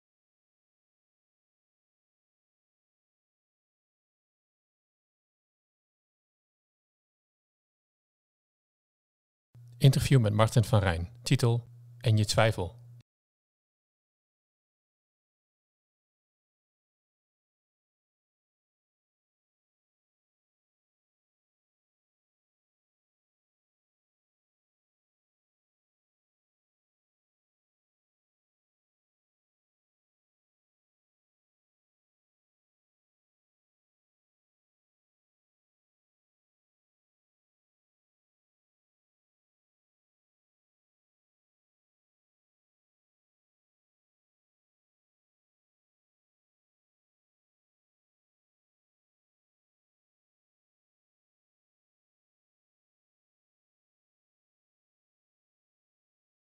Interview met Martin van Rijn.